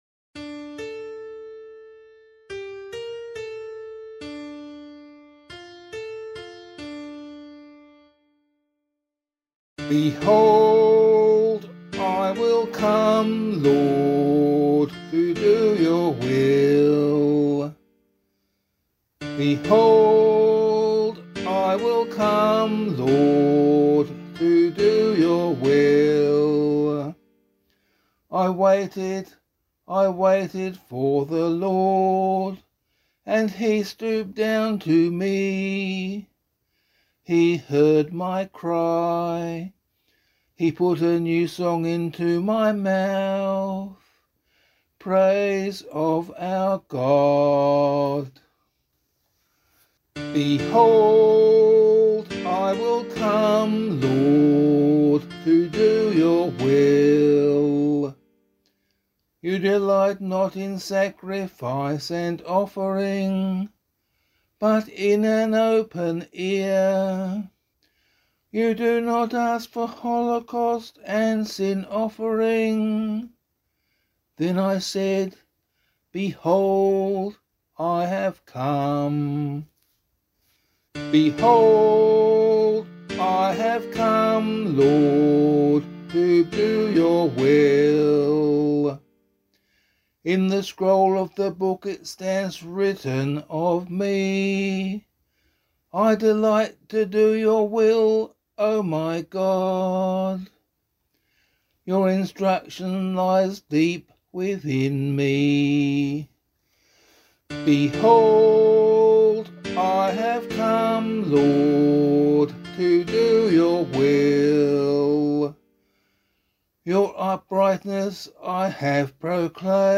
036 Ordinary Time 2 Psalm A [APC - LiturgyShare + Meinrad 4] - vocal.mp3